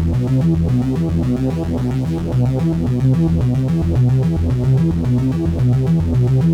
Index of /musicradar/dystopian-drone-samples/Droney Arps/110bpm
DD_DroneyArp2_110-E.wav